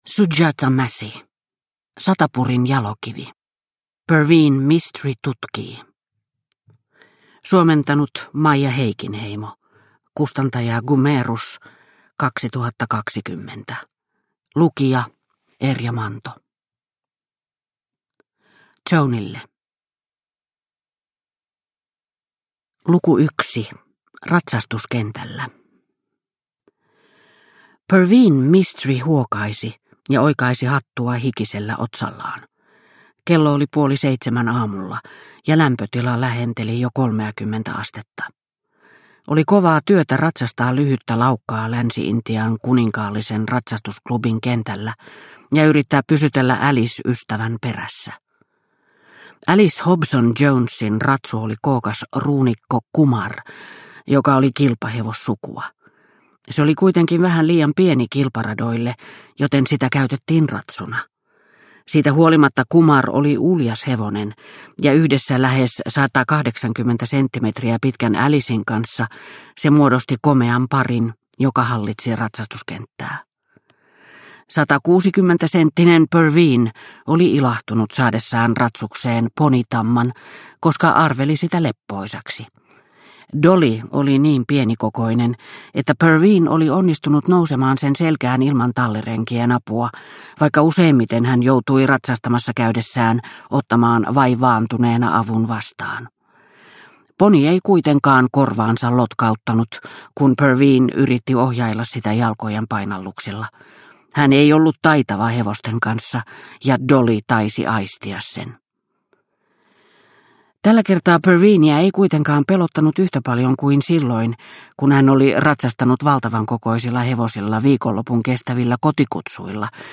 Satapurin jalokivi – Ljudbok – Laddas ner